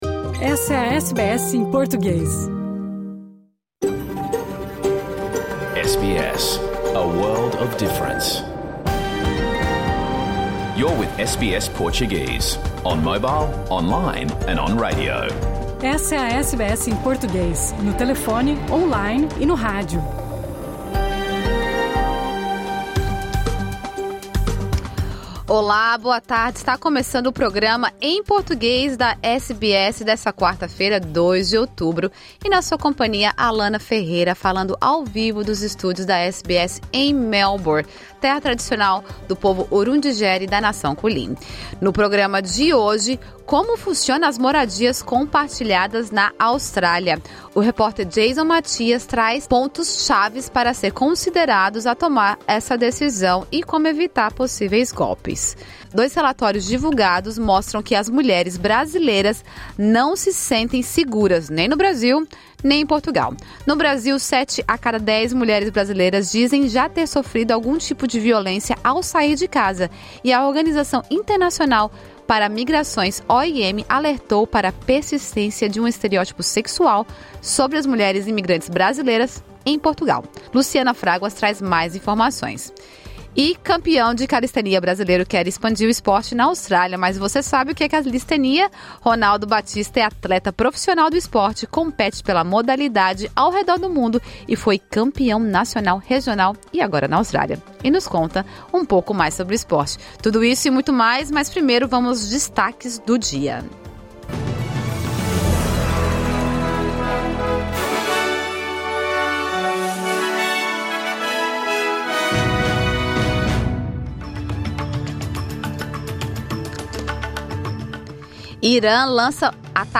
Programa ao vivo | Quarta-feira 02 de outubro